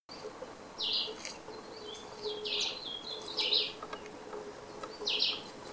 Chivi Vireo (Vireo chivi)
Life Stage: Adult
Location or protected area: Reserva Natural Estricta Quebrada de las Higueritas
Condition: Wild
Certainty: Recorded vocal